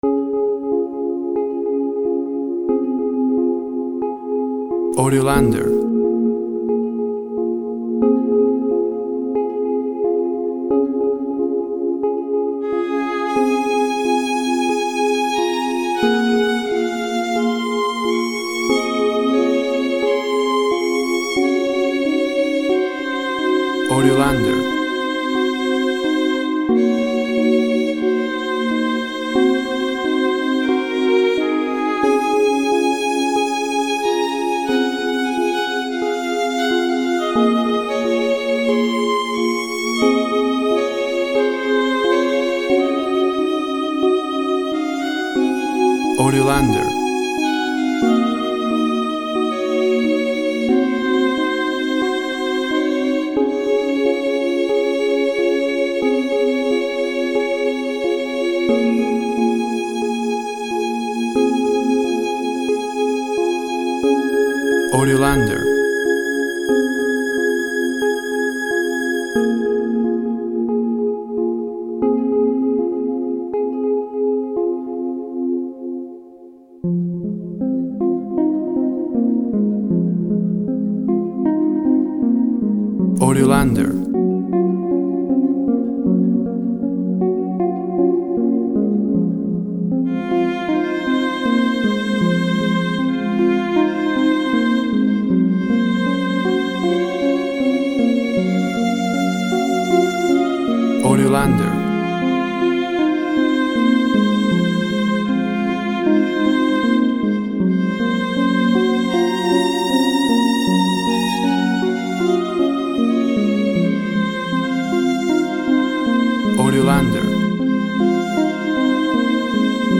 Sweet, dreamy, melancholy violin and synth harp.
Tempo (BPM) 90